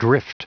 Prononciation du mot drift en anglais (fichier audio)
Prononciation du mot : drift